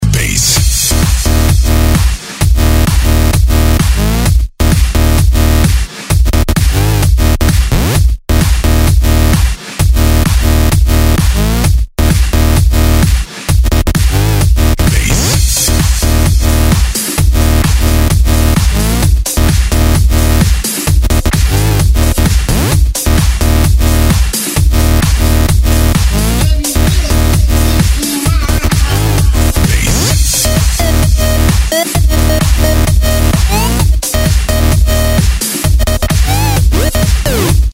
284 Категория: Клубные рингтоны Загрузил